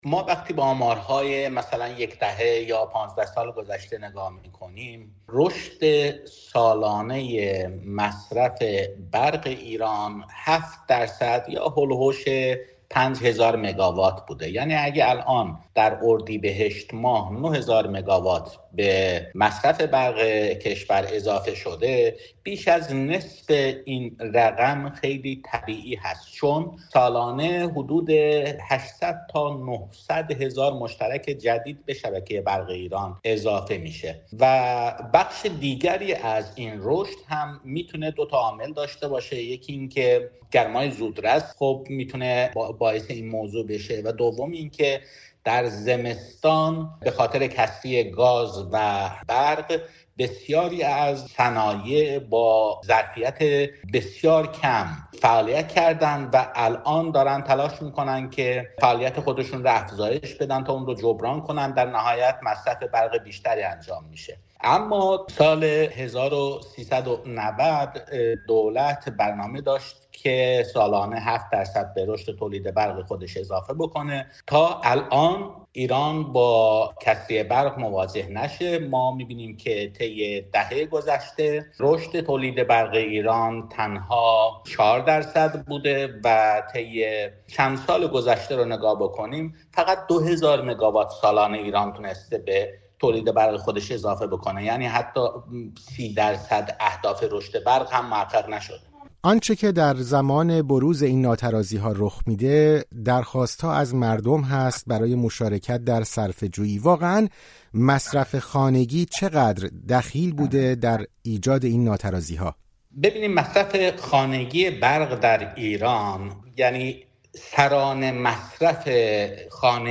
کارشناس انرژی: ایران در کوتاه‌مدت هیچ راه‌حلی برای مشکل برق ندارد